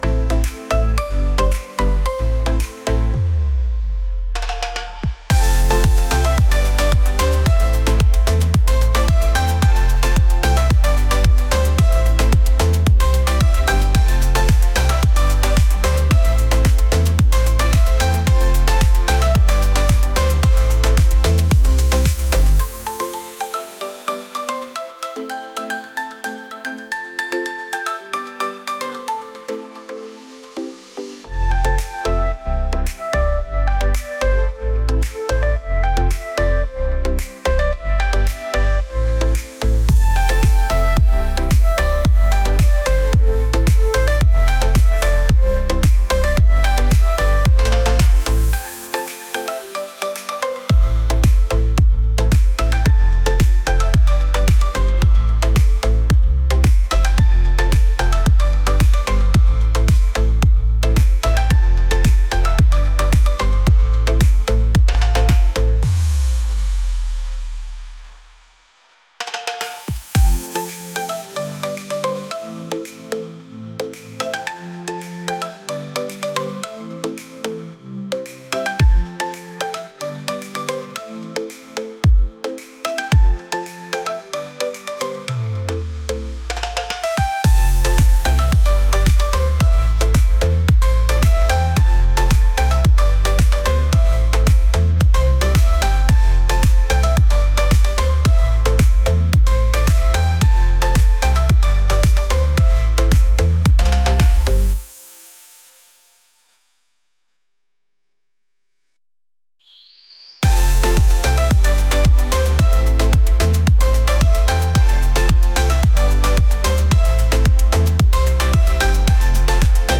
island | vibes